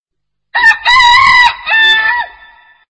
دانلود آهنگ خروس از افکت صوتی انسان و موجودات زنده
دانلود صدای خروس از ساعد نیوز با لینک مستقیم و کیفیت بالا
جلوه های صوتی